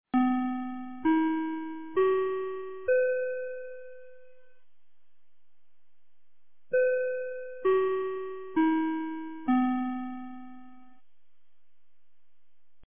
2024年12月29日 14時27分に、東金市より防災行政無線の放送を行いました。